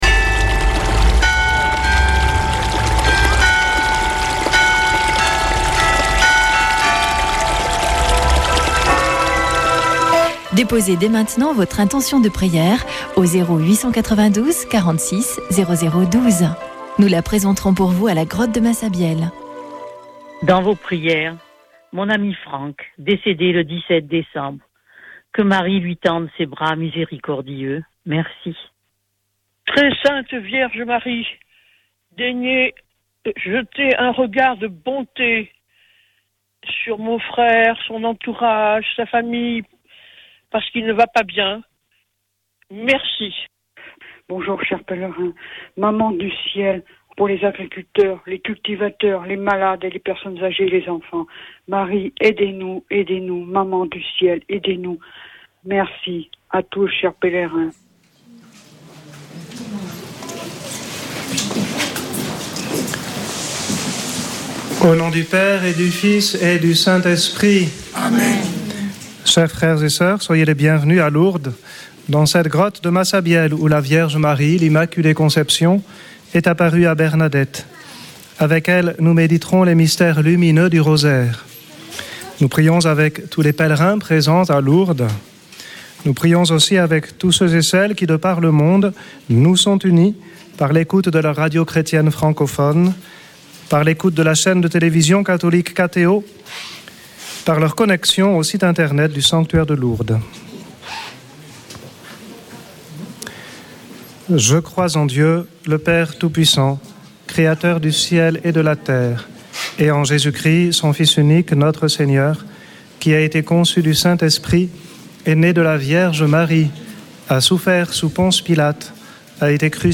Chapelet de Lourdes du 18 déc.
Une émission présentée par Chapelains de Lourdes